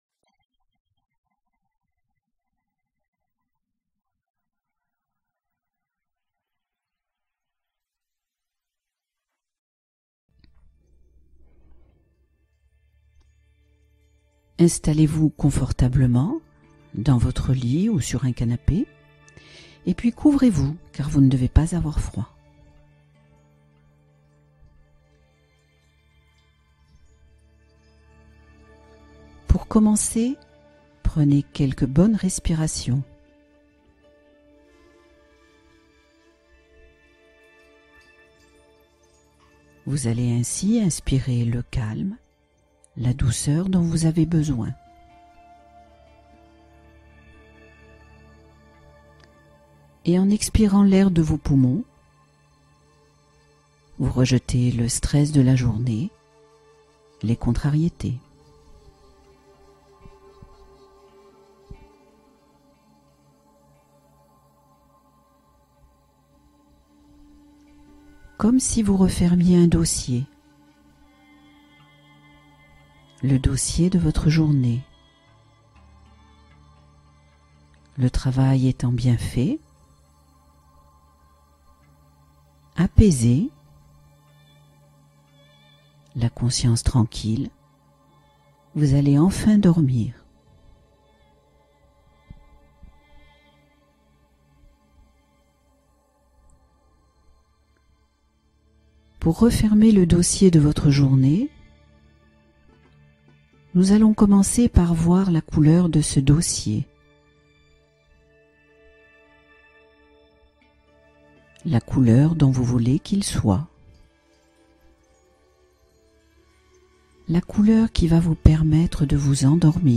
S’endormir rapidement : méditation pour plonger dans le sommeil